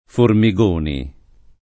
[ formi g1 ni ]